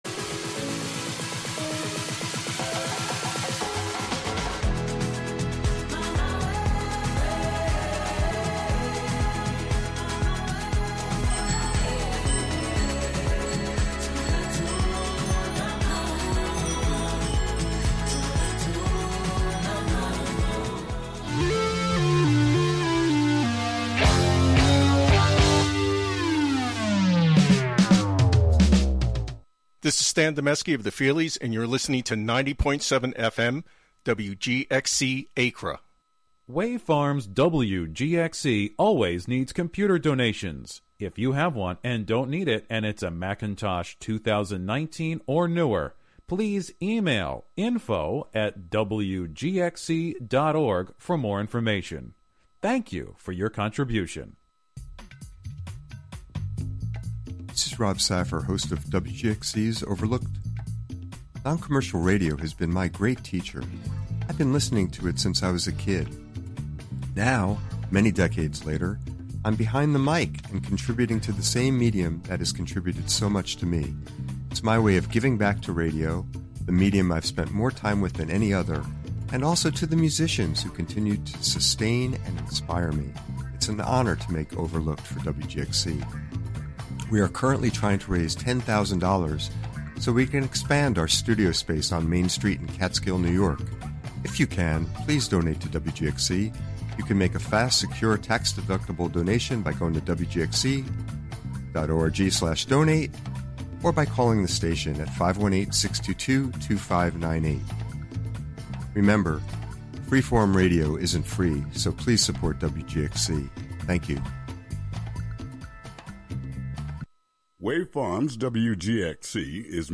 Hosted by various WGXC Volunteer Programmers.
Tune in for special fundraising broadcasts with WGXC Volunteer Programmers!